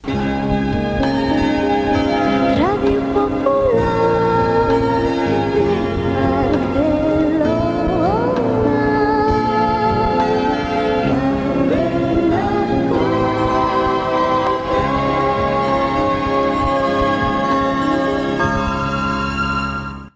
Indicatiu de Nadal de l'emissora.
FM